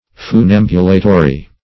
Meaning of funambulatory. funambulatory synonyms, pronunciation, spelling and more from Free Dictionary.
Search Result for " funambulatory" : The Collaborative International Dictionary of English v.0.48: Funambulatory \Fu*nam"bu*la`to*ry\, a. 1.